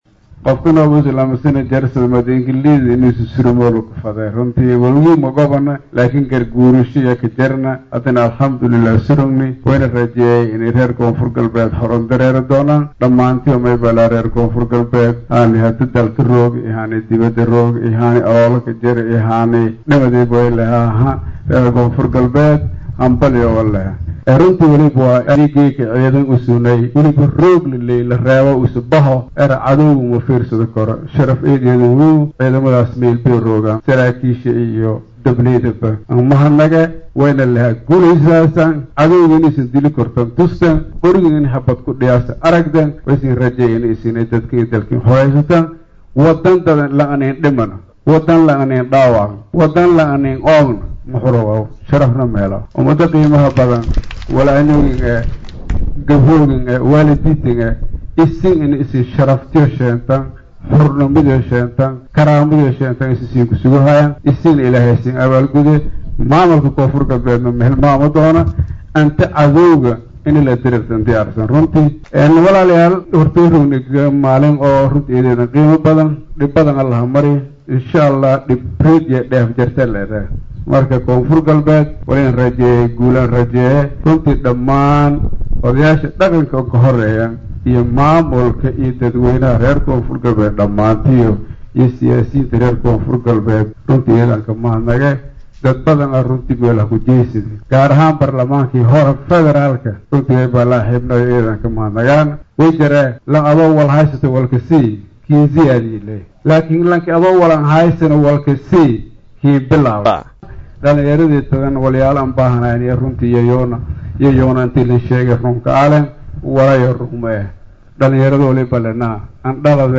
Baydhabo(INO)- Madaxweynaha Dowlada Koonfur Galbeed Soomaaliya ayaa khudbadiisa Sanad Guuradii  2aad ee Aas Aaska Dowlada Koonfur Galbeed Soomaaliya ugu Hadley, Arimo Dhowr ah sida; Amaanka ,Horumarka iyo Arimaha Siyaasada.